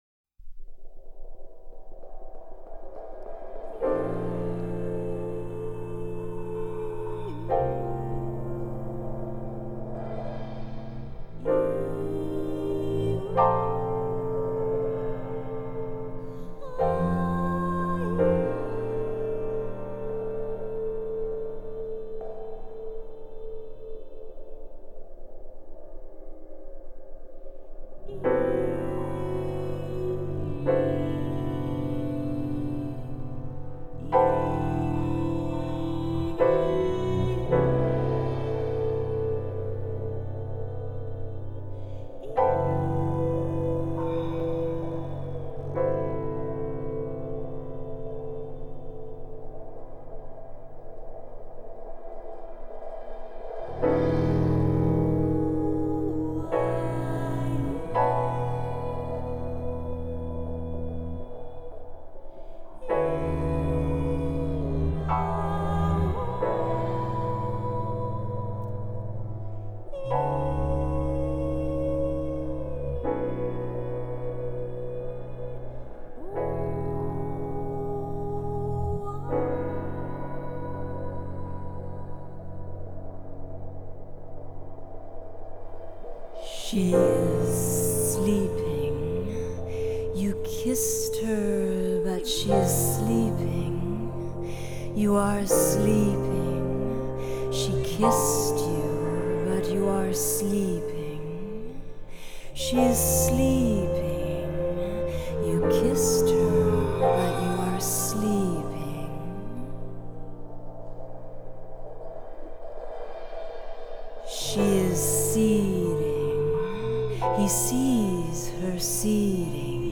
Recorded at CBC Studio 11 in Winnipeg